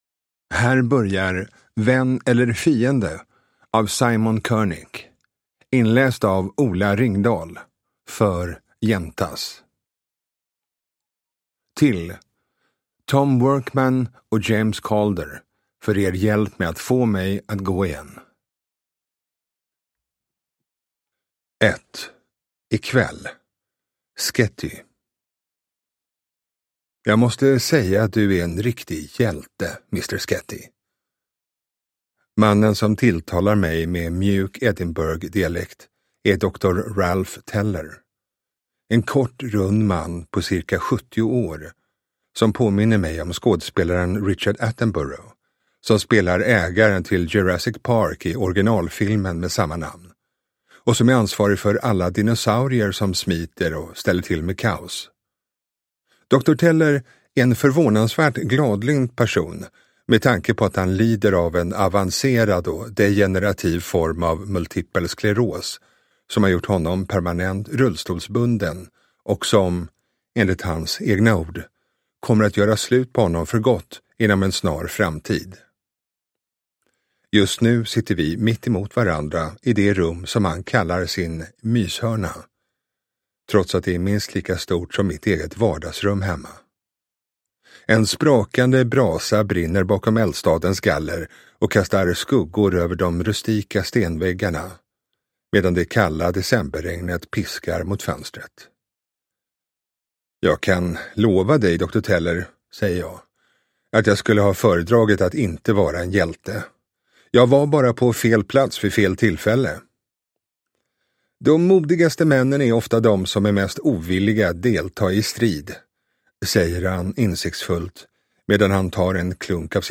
Deckare & spänning
Ljudbok